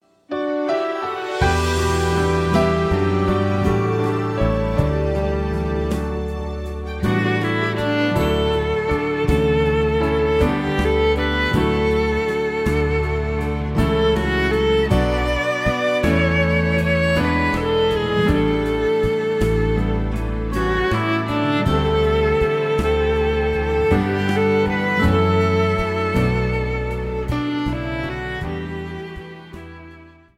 Recueil pour Violon